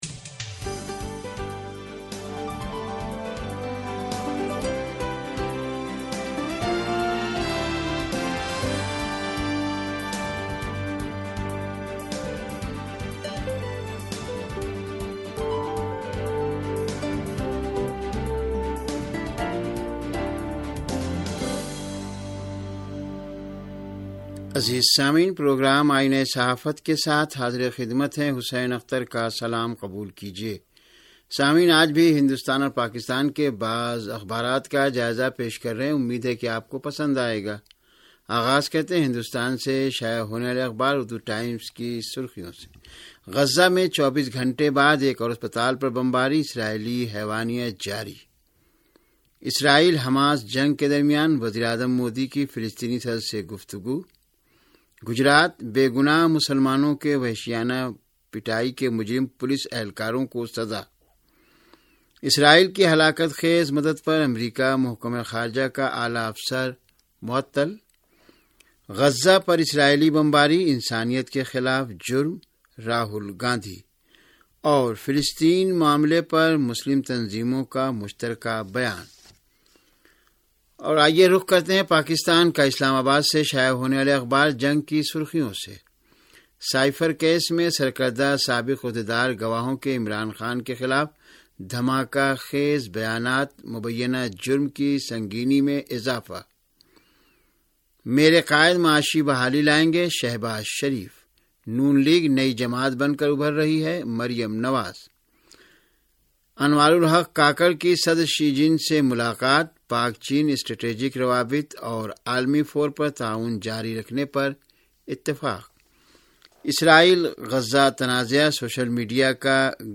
ریڈیو تہران کا اخبارات کے جائزے پرمبنی پروگرام - آئینہ صحافت